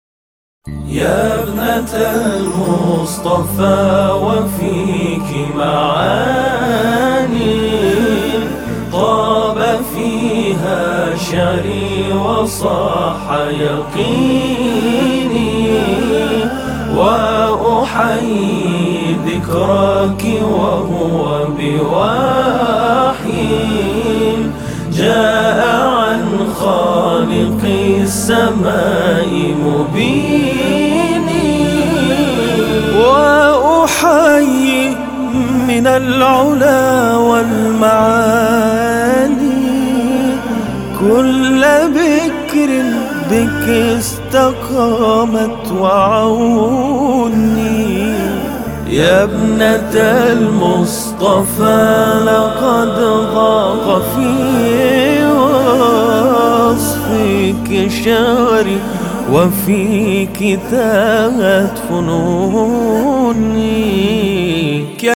مبتهل ، تواشیح